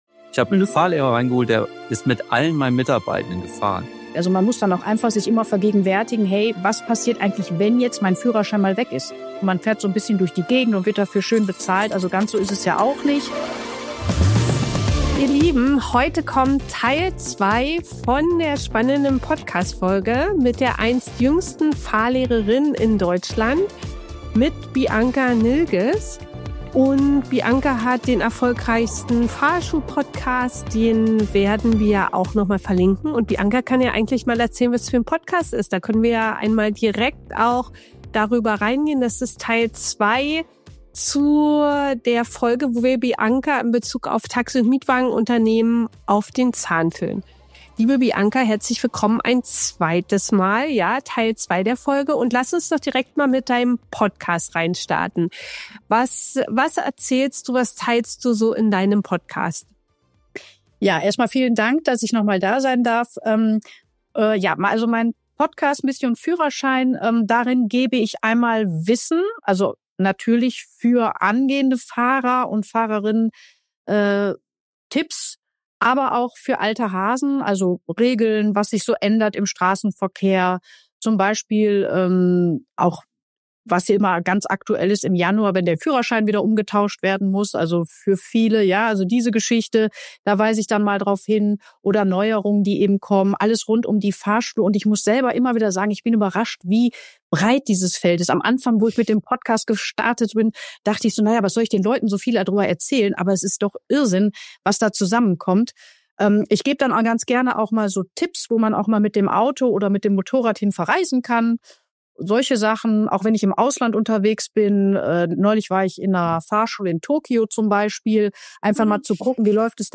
Ist Teflon auch dein Fahrstil? Interview